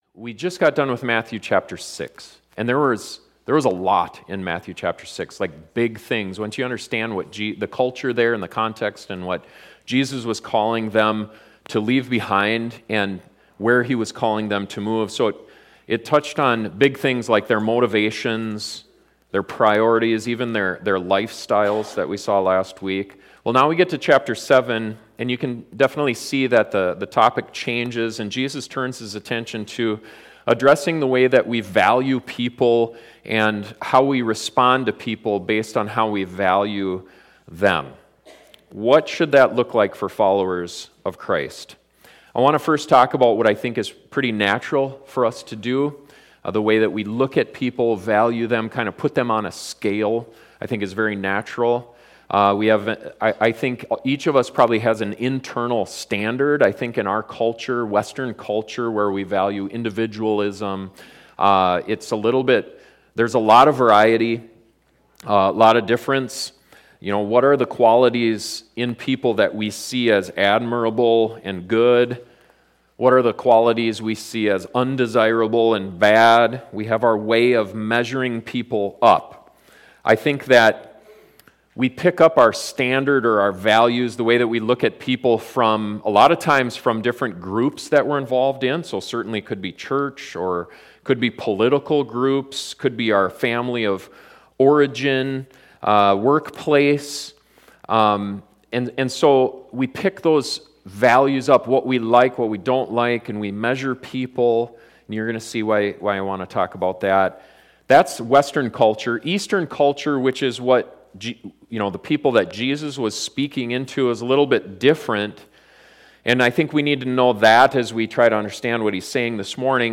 Are Christians supposed to judge other people? This sermon looks at a saying of Jesus that is often misunderstood and misused and helps clarify what he actually intended to say.